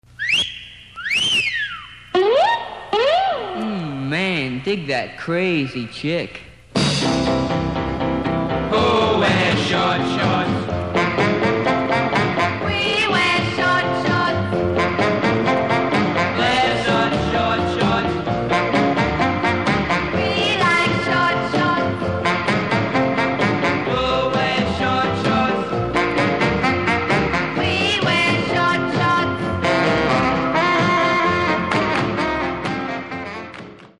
男性コーラス・女性コーラスにガラクタ箱をひっくりかえしたようなサックスがからむ